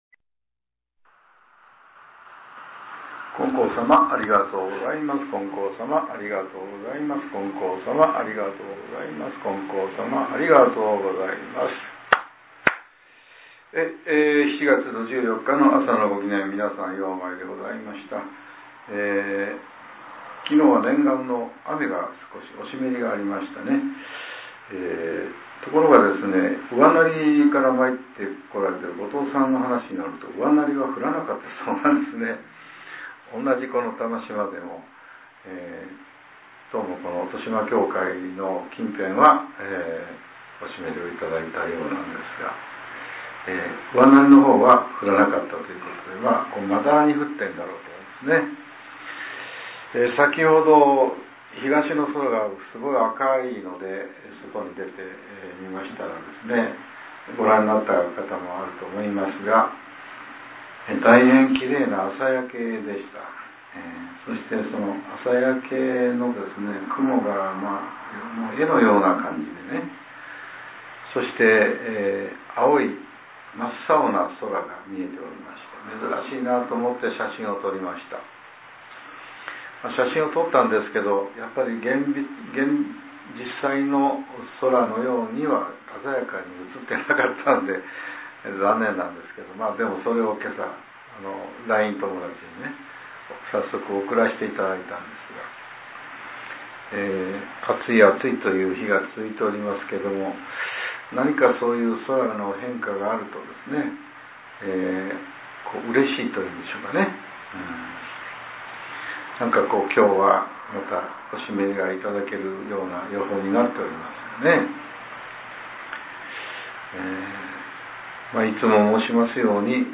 令和７年７月１４日（朝）のお話が、音声ブログとして更新させれています。